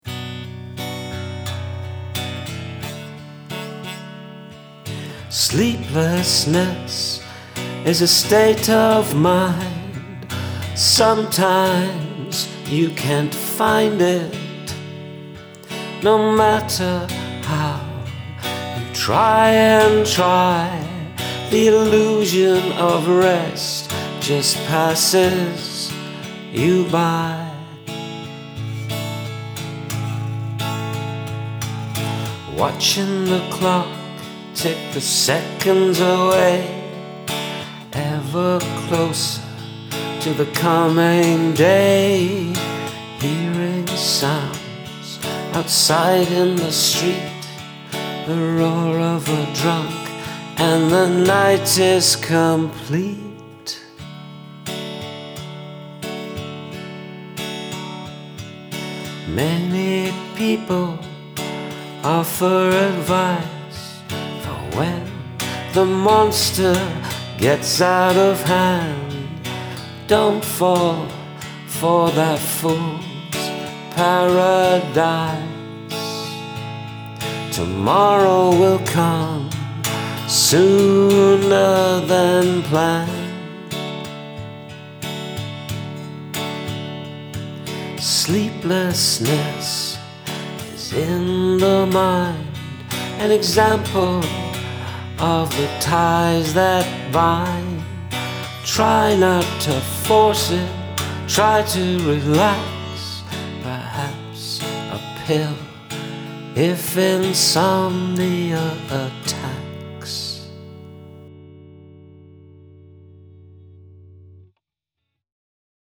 I love the hopeful feel in your song.